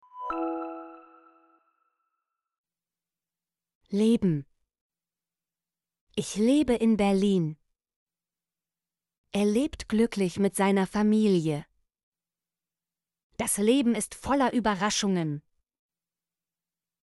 leben - Example Sentences & Pronunciation, German Frequency List